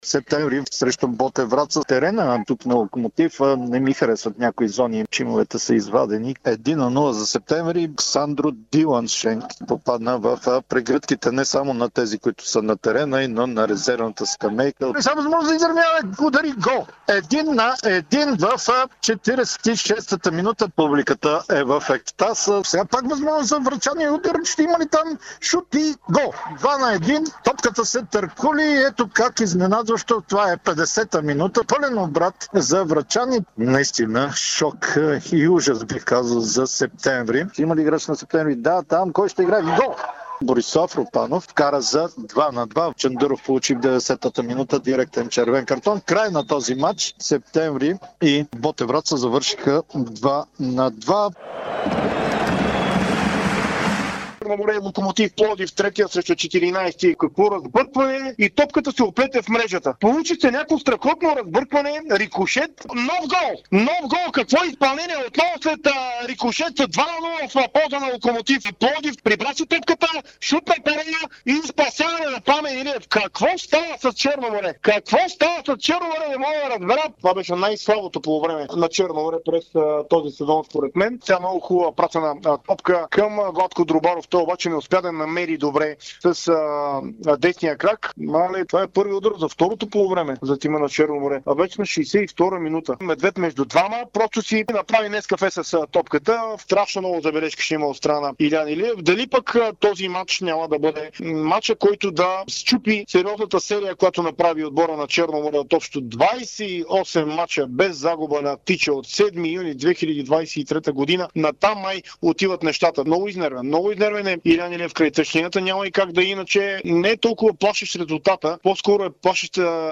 Традицията повелява всички двубои да може да следите в ефира на Дарик. А какво чухме от футболните терени?